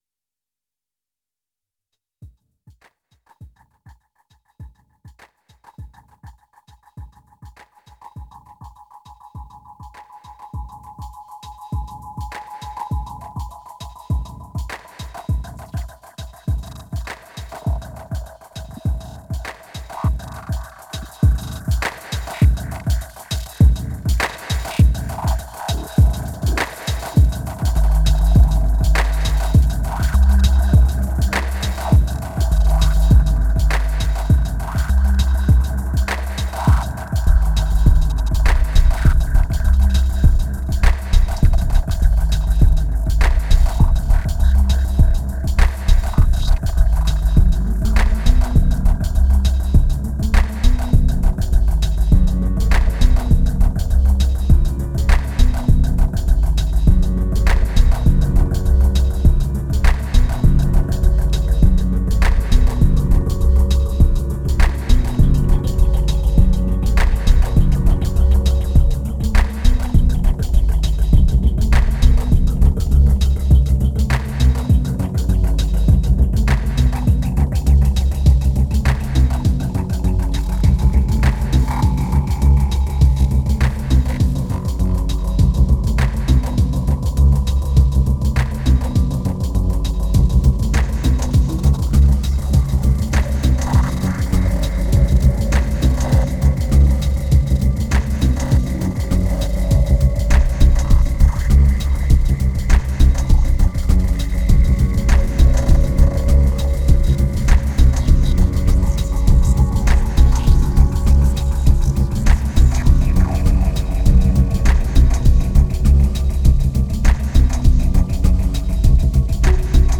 1981📈 - -51%🤔 - 101BPM🔊 - 2010-12-04📅 - -252🌟